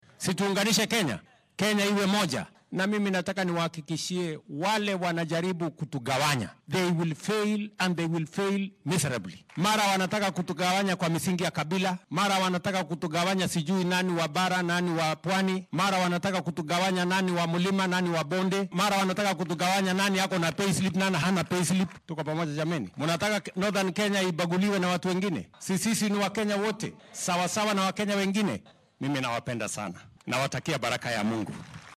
Madaxweynaha ayaa xilli uu hadal ka jeedinayay machadka tababarka macallimiinta ee Mandera sheegay in berri uu guddoomin doono munaasabad ballaaran oo meesha looga saaraya turxaan bixinta la mariyo shacabka gobolka marka ay codsanayaan aqoonsiga qaran iyo baasaboorka.